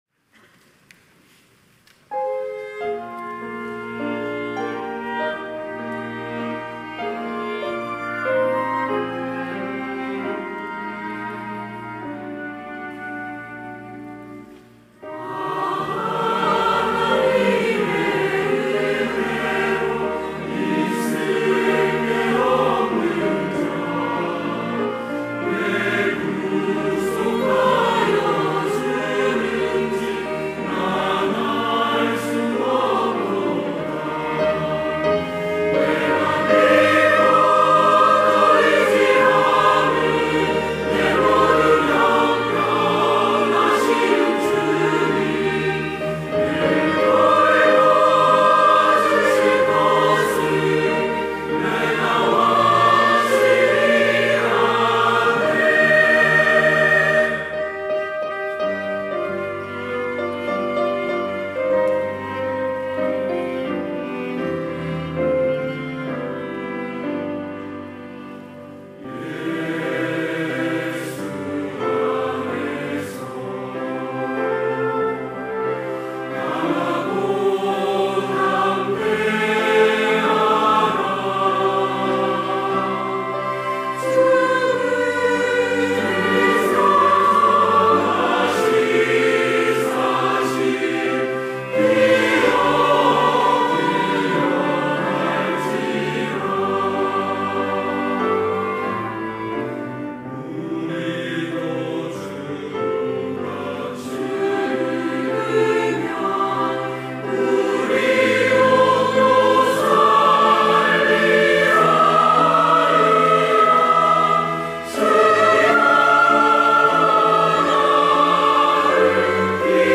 호산나(주일3부) - 아 하나님의 은혜로
찬양대